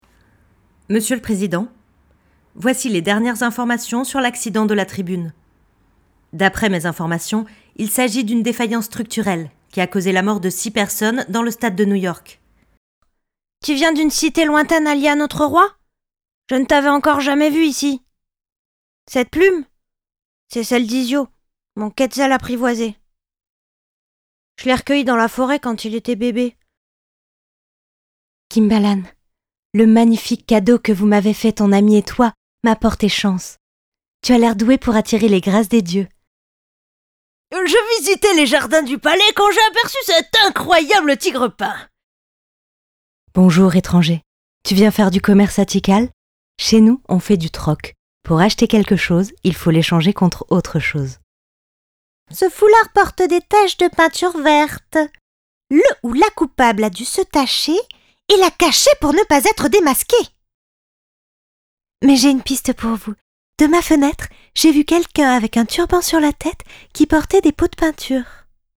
Compil voix jeu
Voix off
15 - 65 ans - Contralto Mezzo-soprano